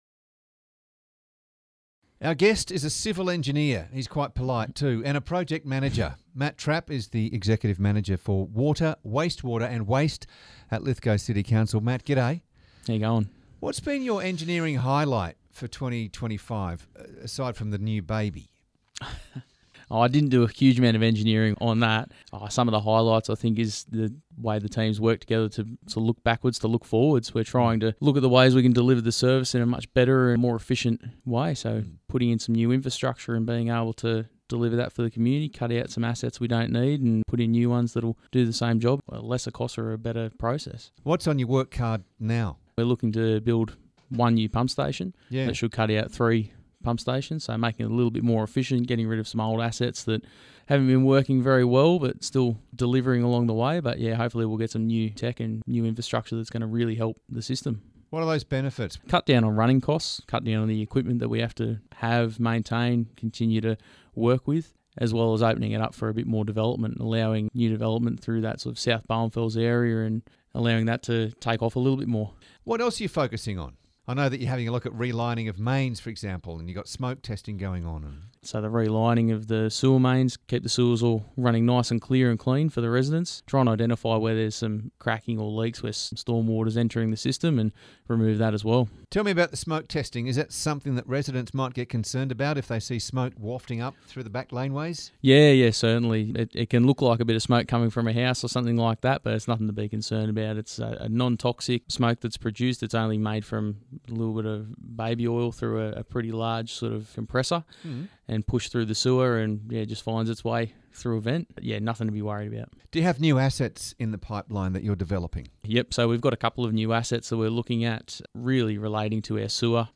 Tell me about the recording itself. Audio courtesy of 2LT and Move FM